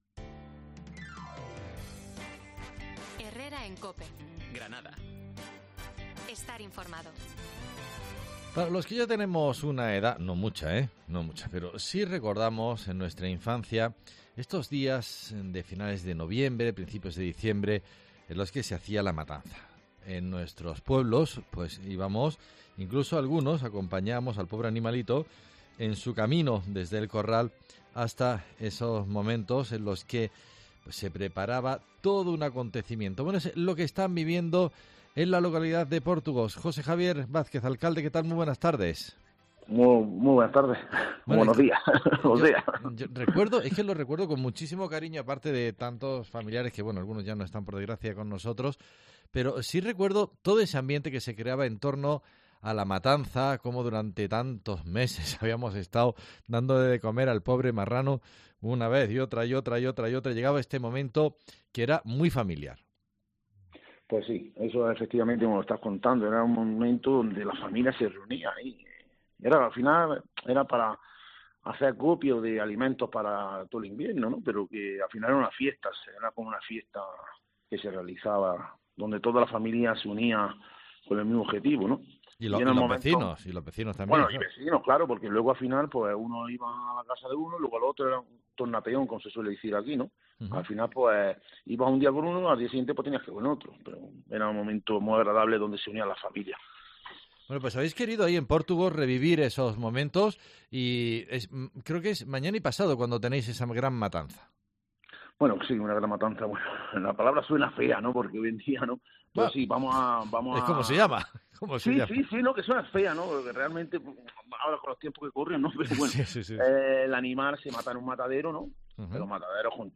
Jose Javier Vázquez, alcalde de Pórtugos nos habla de la fiesta de la matanza